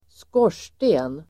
Ladda ner uttalet
Uttal: [²sk'år_s:te:n]
skorsten.mp3